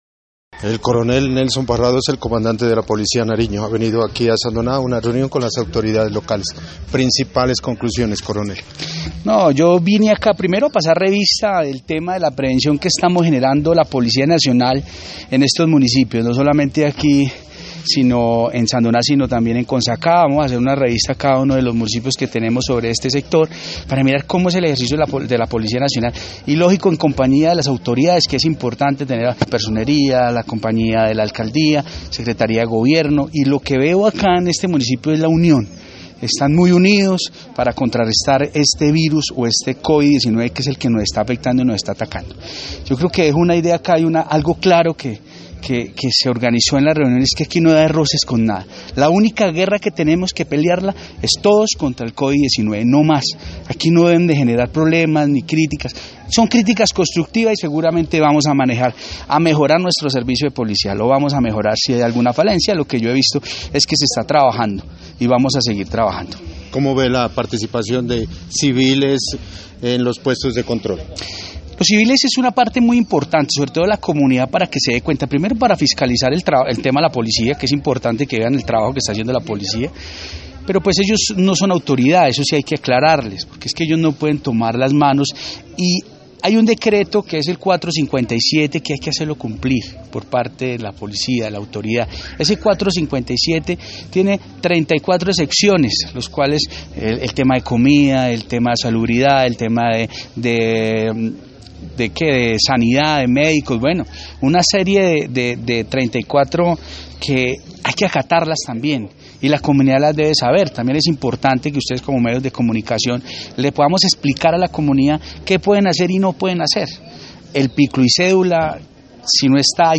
Entrevista con el coronel Nelson Parrado, comandante del Departamento de Policía Nariño: